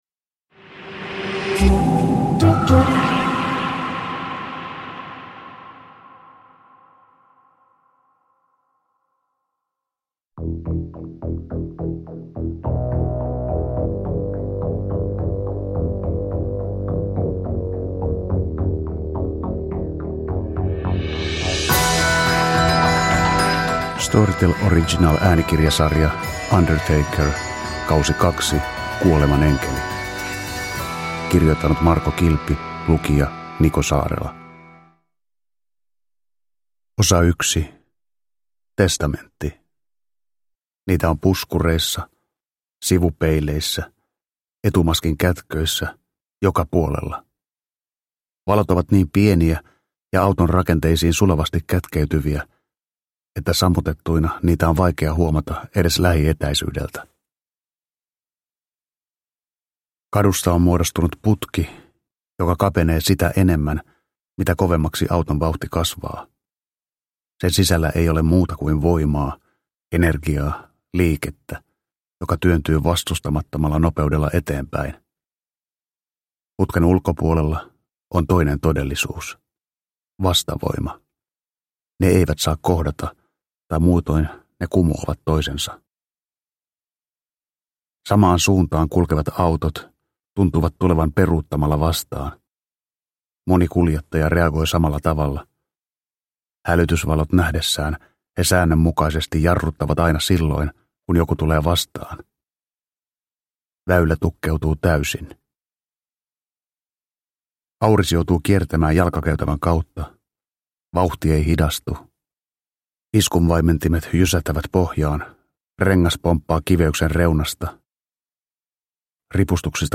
Undertaker 2 - Kuolemanenkeli – Ljudbok – Laddas ner